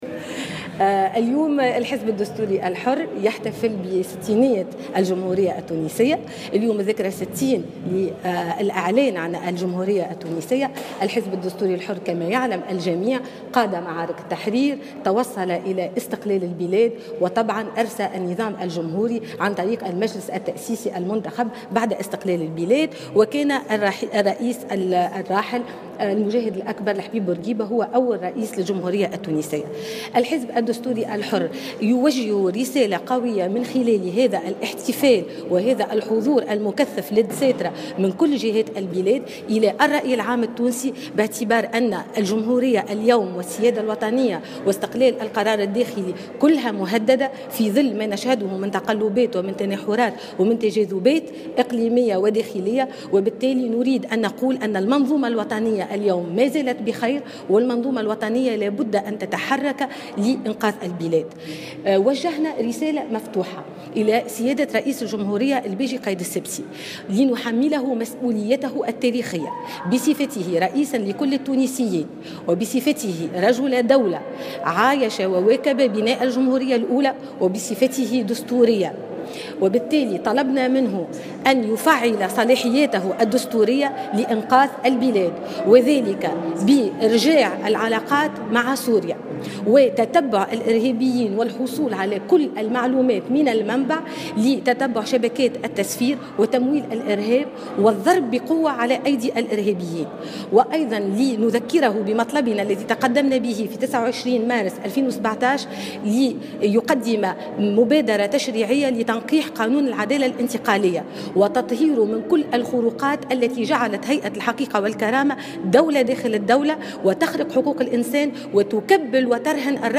وقالت عبير موسي في تصريح لمراسل الجوهرة "اف ام" أن طالبت رئيس الجمهورية بتفعيل صلاحياته الدستورية لإنقاذ البلاد وذلك بإرجاع العلاقات مع سوريا وتتبع الإرهابيين والحصول على المعلومات من المنبع .